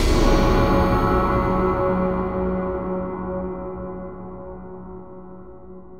Synth Impact 19.wav